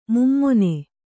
♪ mummone